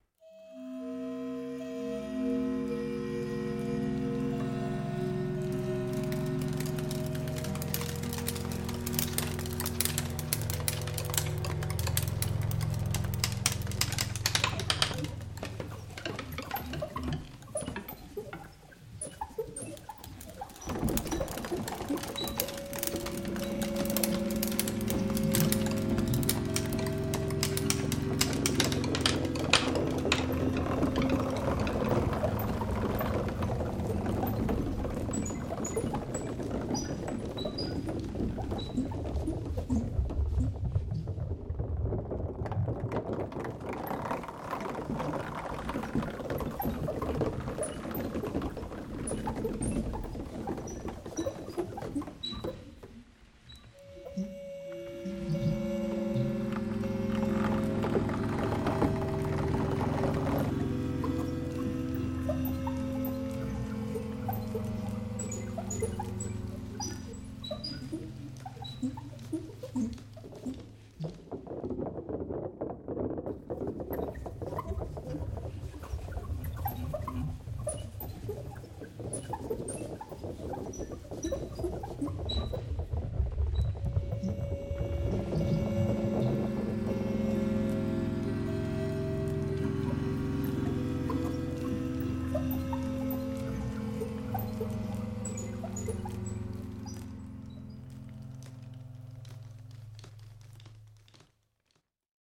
Bild: Acryl, Kreiden, Oilstick, Grafit auf Holz, 184 x 85 cm                                                                              Sound: Kontrabasssamples, Ableton Live